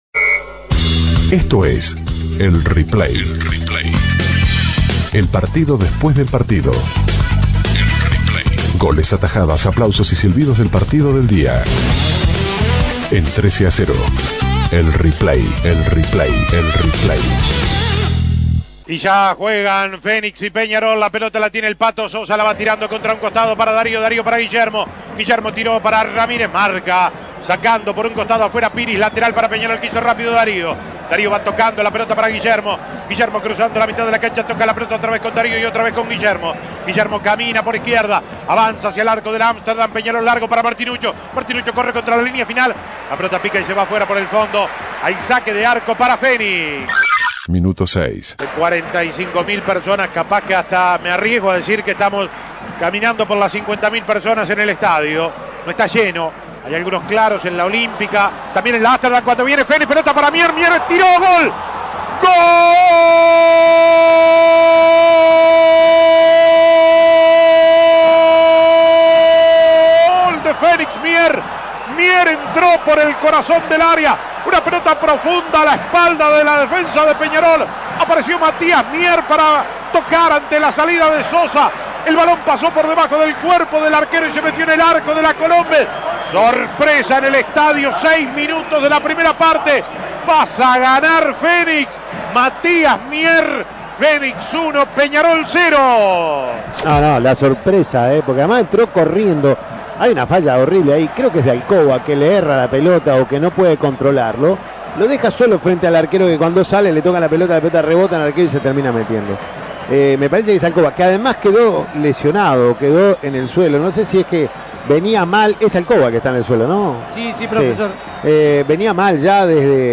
Goles y comentarios El Replay del partido Fénix-Peñarol Imprimir A- A A+ Escuche los goles y las principales incidencias del partido entre Fénix y Peñarol.